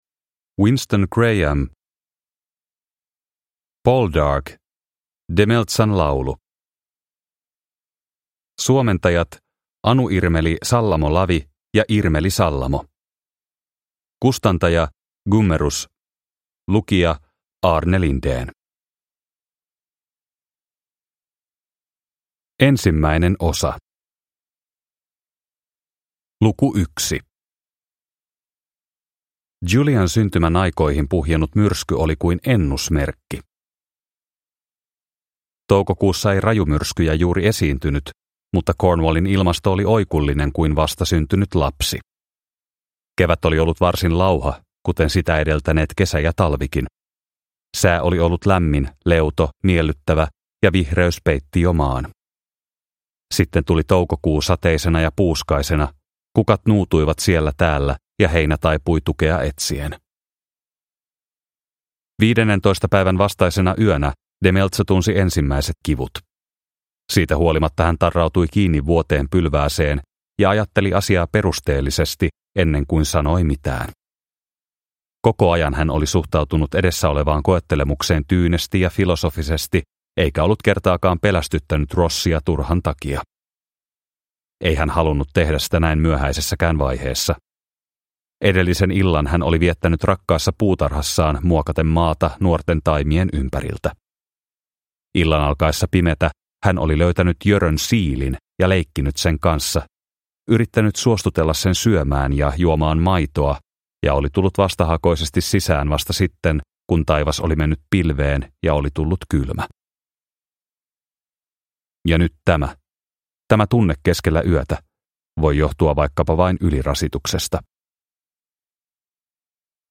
Poldark - Demelzan laulu – Ljudbok – Laddas ner